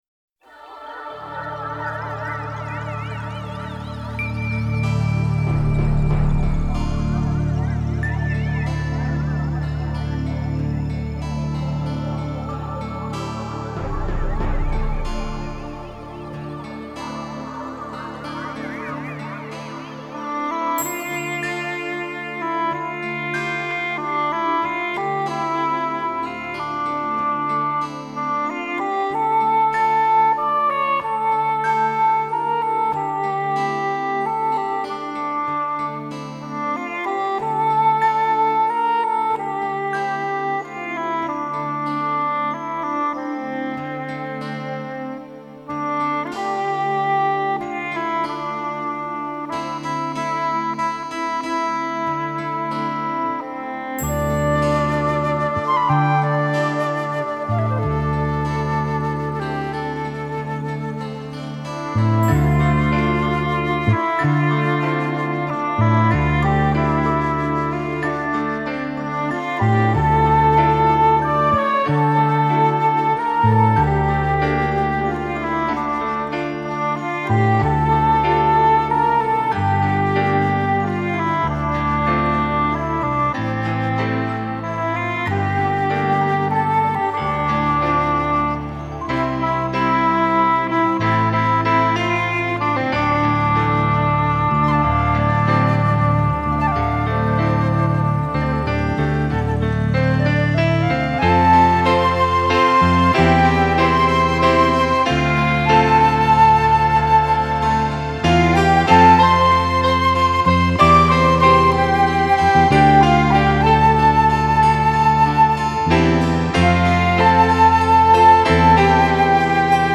New age Нью эйдж Релакс музыка Музыка релакс